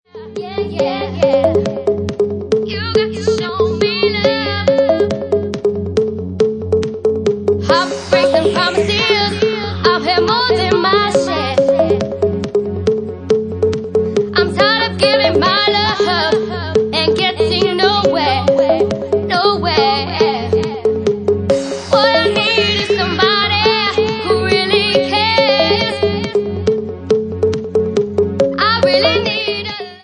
Bassline House at 137 bpm